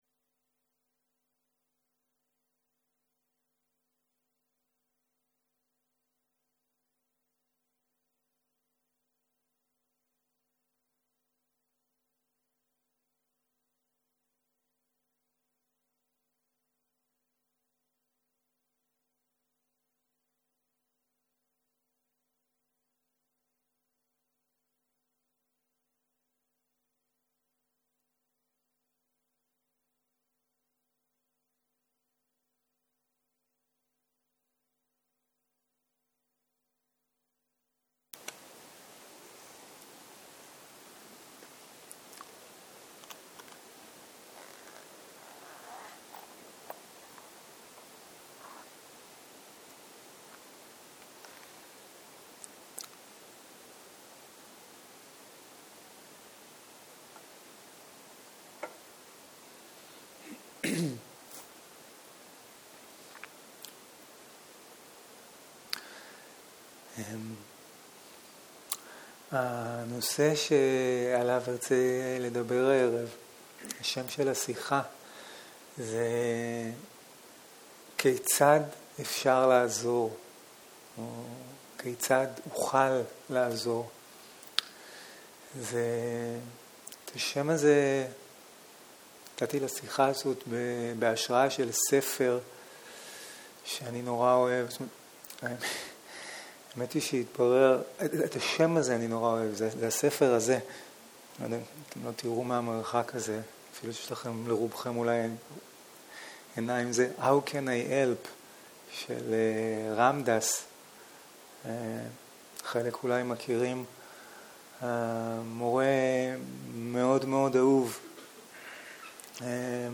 ערב - שיחת דהרמה - איך אוכל לעזור - שיחה 15